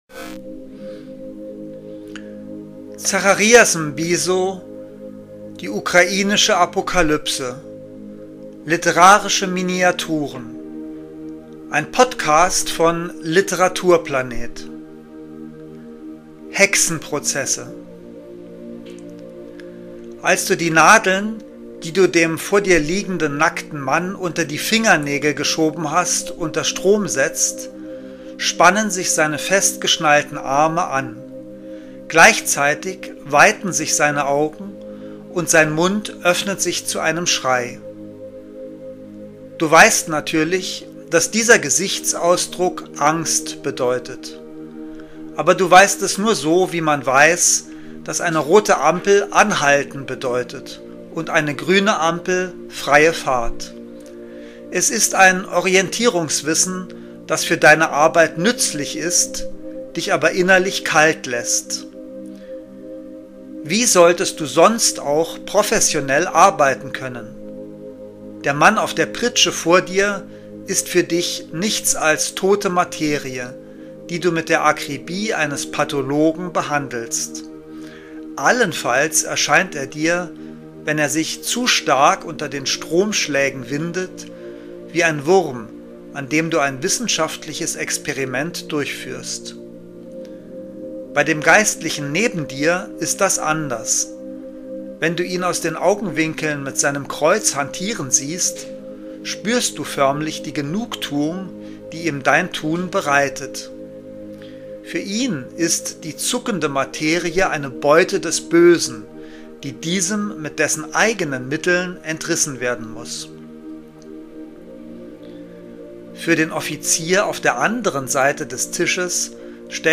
Lesungen